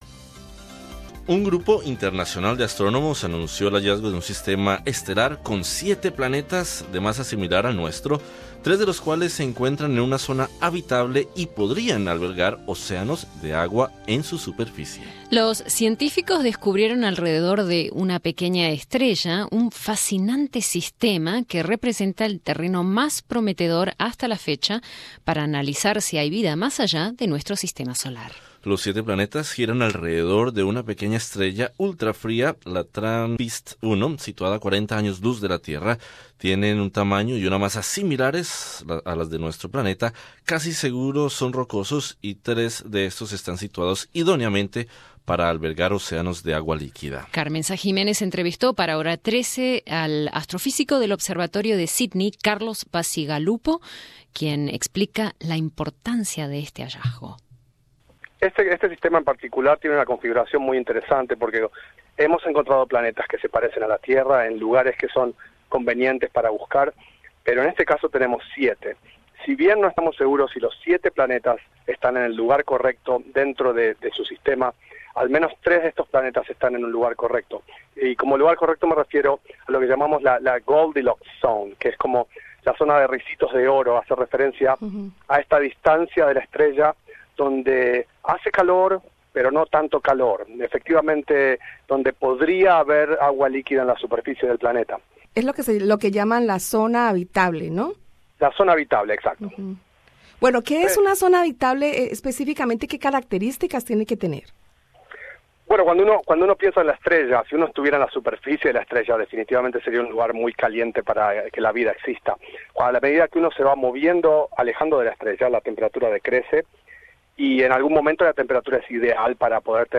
Escucha arriba el podcast con la entrevista